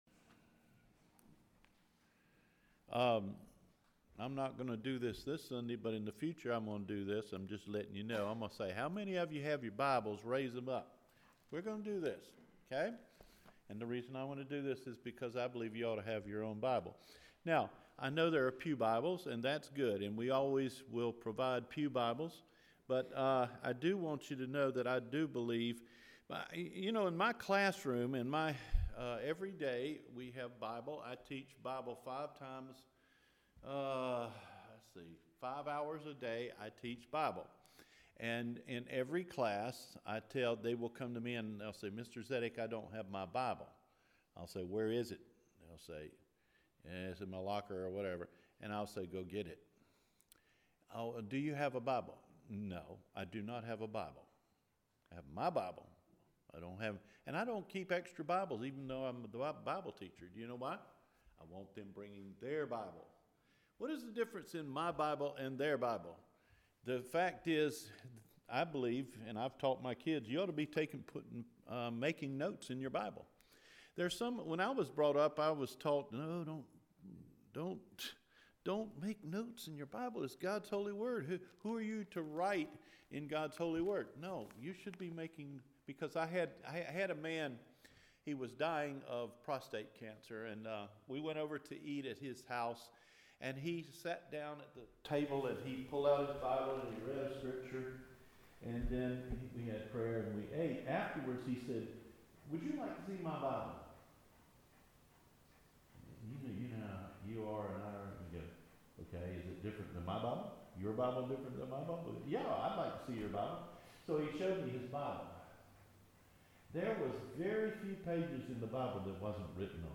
The Power of Encouragement – March 18, 2018 Recorded Sermon